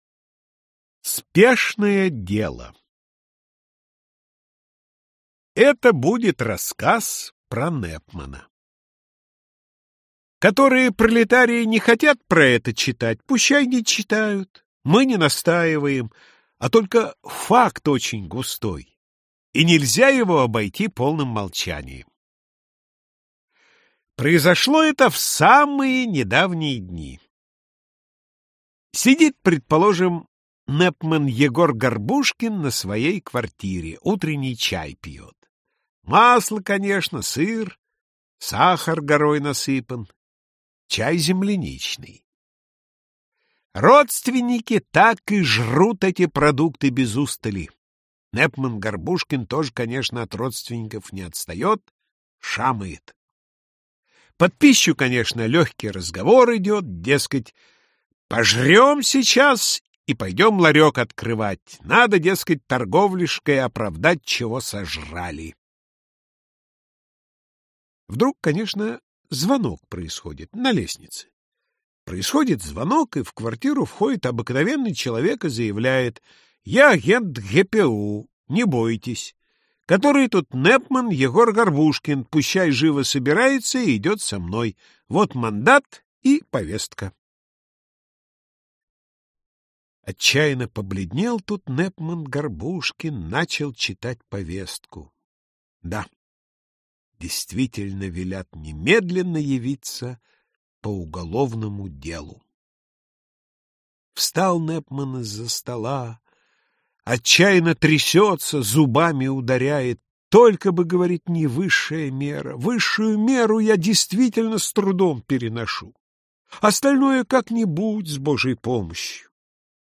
Аудиокнига Рассказы | Библиотека аудиокниг